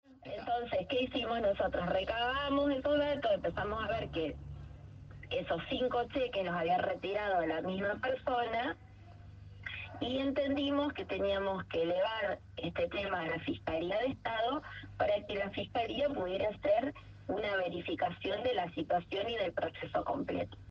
La vicegobernadora Laura Stratta eligió Radio LT 39 de Victoria para intentar minimizar el escándalo de los subsidios truchos que la tiene como protagonista y que ha generado un enorme escándalo en la ciudad de Victoria y en toda la Provincia.
Entrevista-a-Stratta-5.mp3